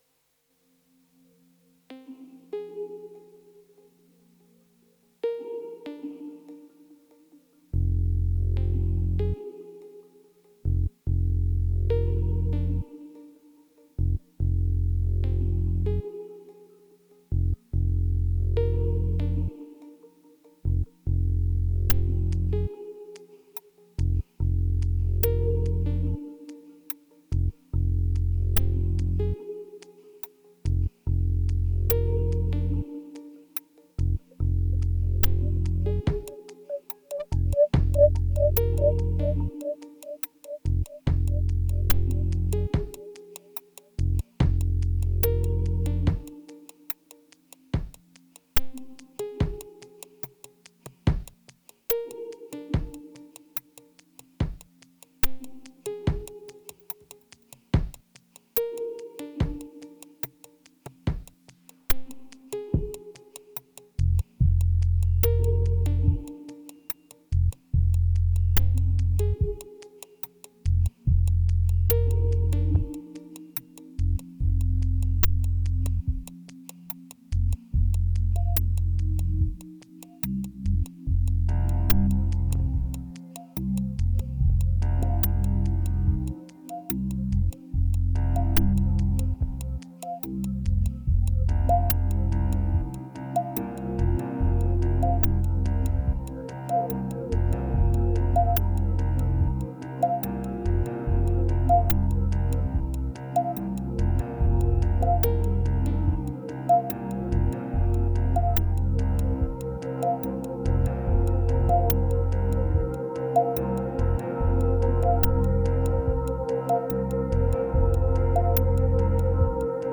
2457📈 - 82%🤔 - 72BPM🔊 - 2017-06-04📅 - 916🌟